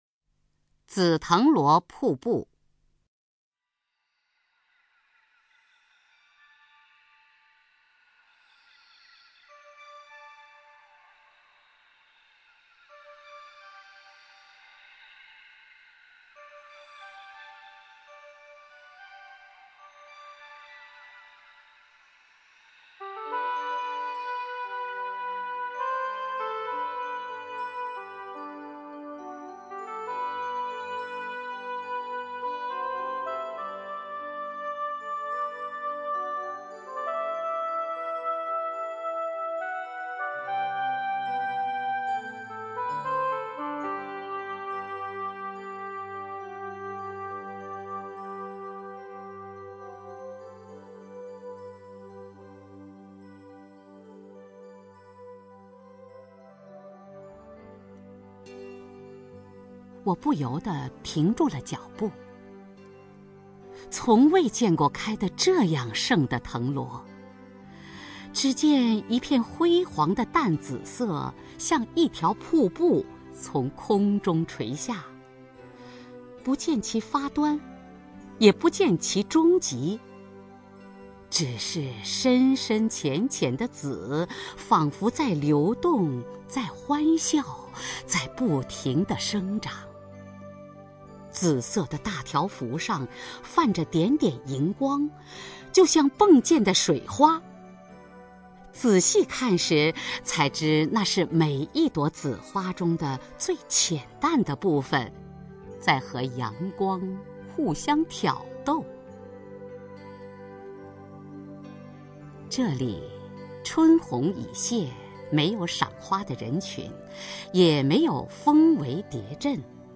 首页 视听 名家朗诵欣赏 雅坤
雅坤朗诵：《紫藤萝瀑布》(宗璞)